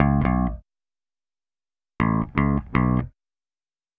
Power Pop Punk Bass Ending.wav